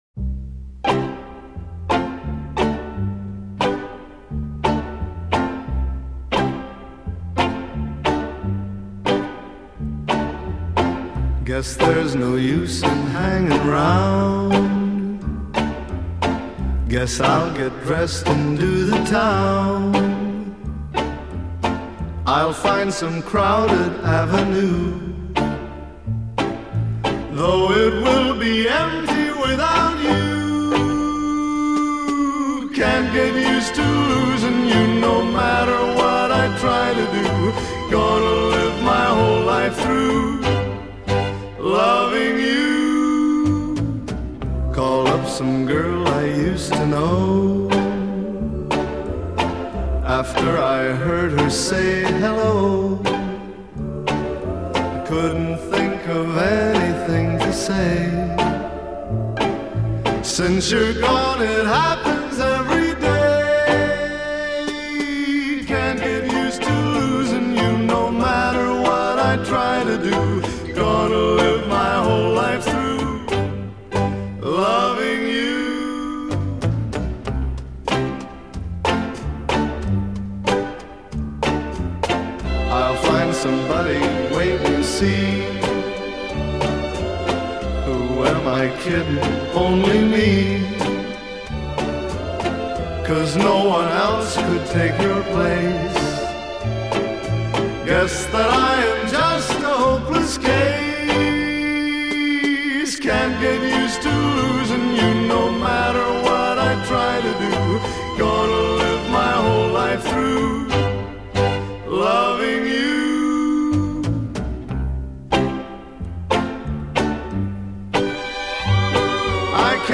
Traditional Pop / Vocal Pop / Easy Listening